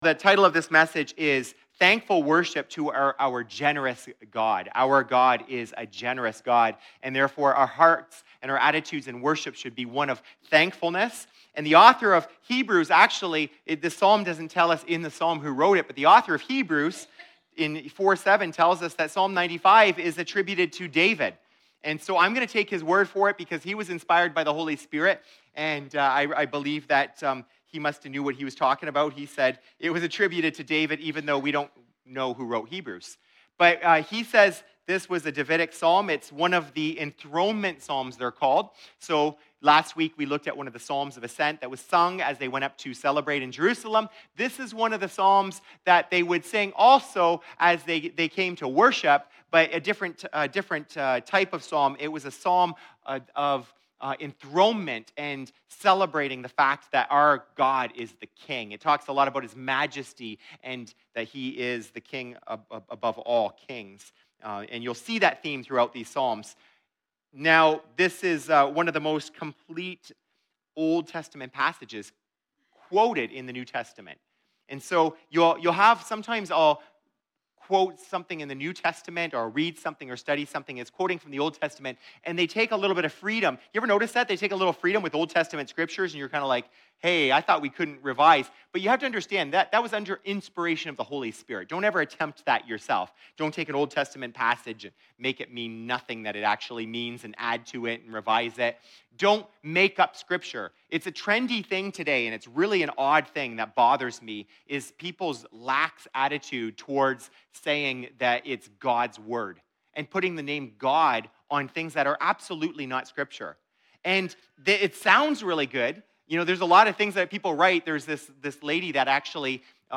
A message from the series "Book of James." In Chapter 4 Vs 13-17, James teaches us to reject "Practical Atheism". We must acknowledge that God is sovereign and our plans should be made with the consideration of His will.